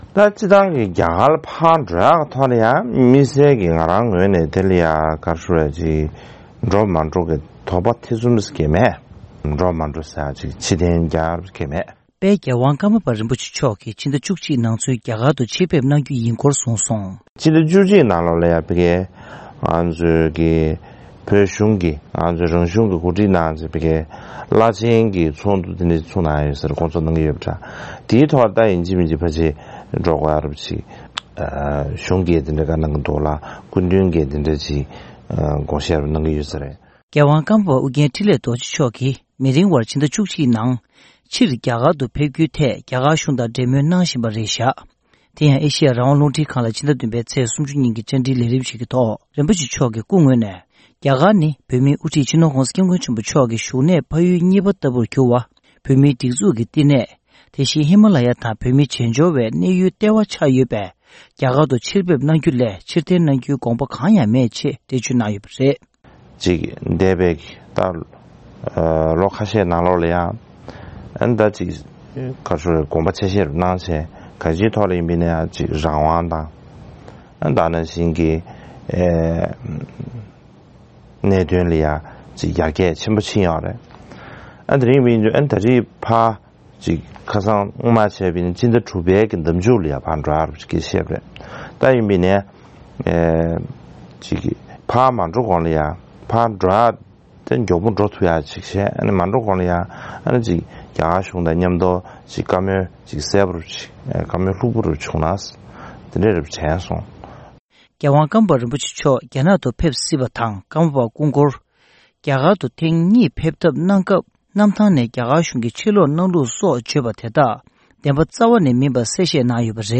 དེ་ཡང་ཨེ་ཤི་ཡ་རང་དབང་རླུང་འཕྲིན་ཁང་ལ་ཕྱི་ཟླ་ ༧ ཚེས་ ༣༠ ཉིན་གྱི་བཅར་འདྲིའི་ལས་རིམ་ཞིག་གི་ཐོག།